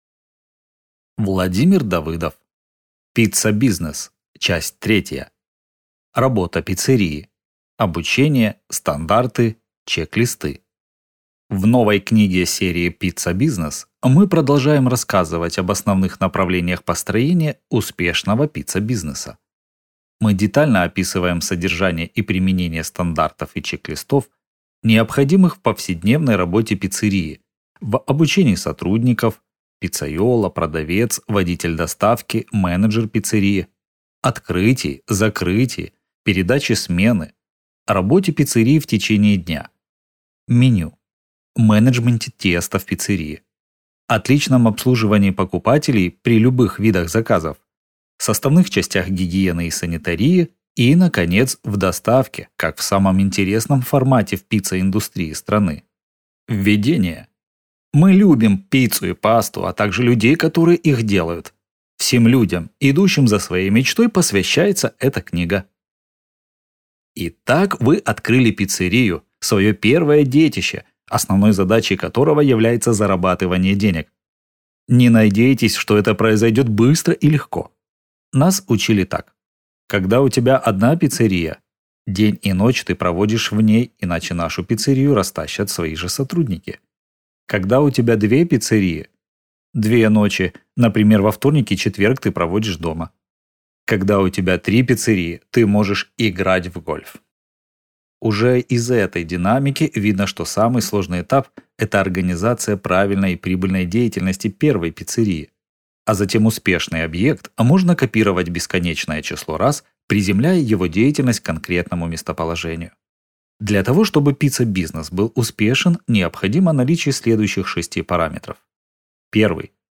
Аудиокнига Пицца-бизнес. Часть 3. Работа пиццерии (обучение, стандарты, чек-листы) | Библиотека аудиокниг